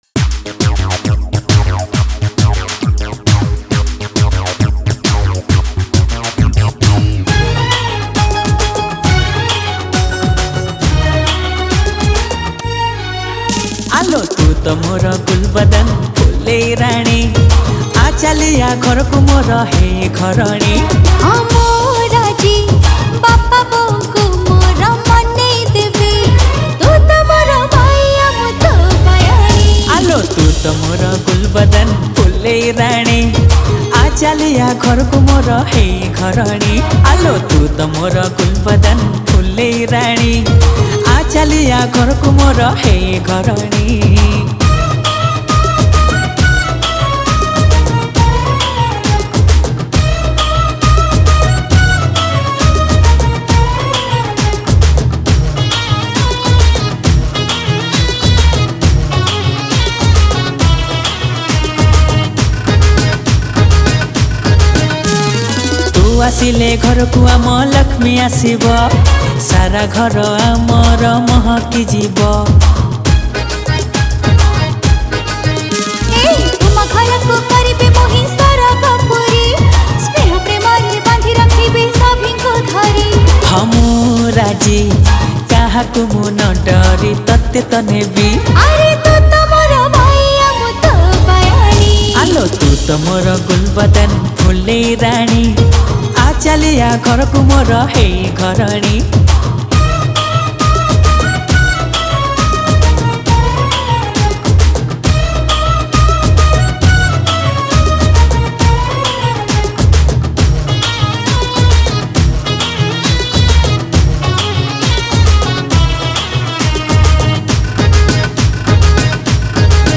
Recorded At: JCK Studio, CDA, Cuttack